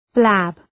Προφορά
{blæb}